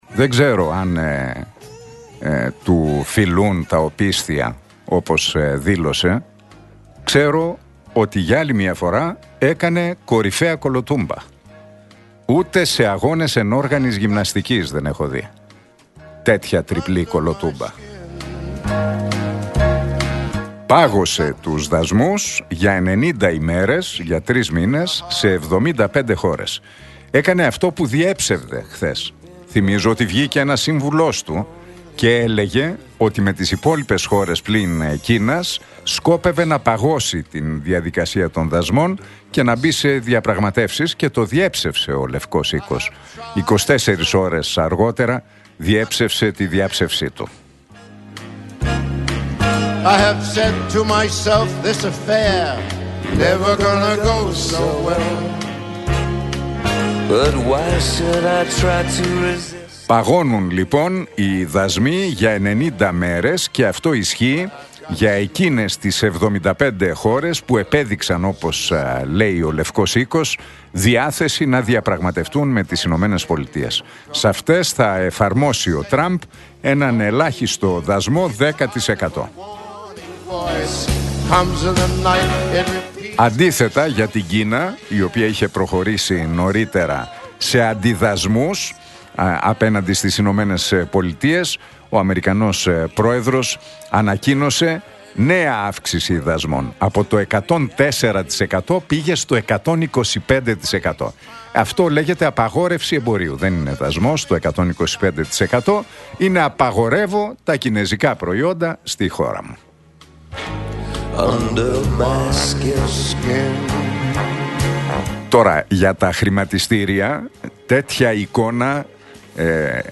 Ακούστε το σχόλιο του Νίκου Χατζηνικολάου στον ραδιοφωνικό σταθμό Realfm 97,8, την Πέμπτη 10 Απριλίου 2025.